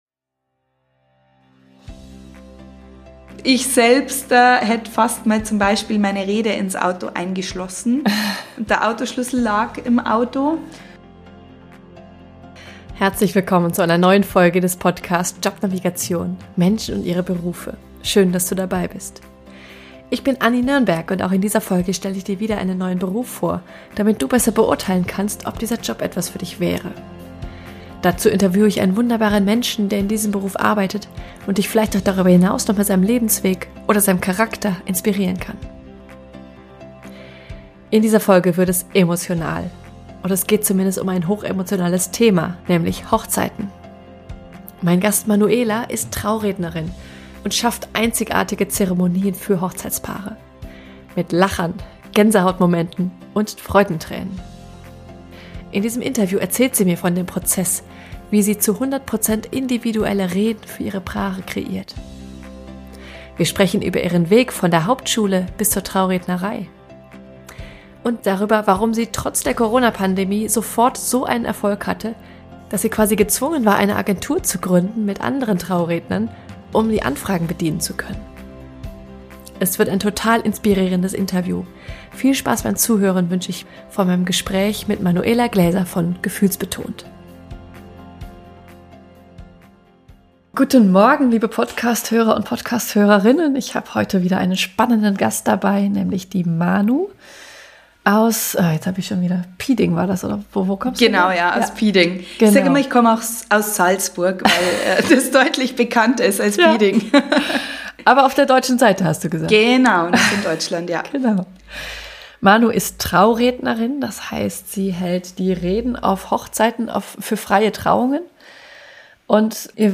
Es wird ein total inspirierendes Interview!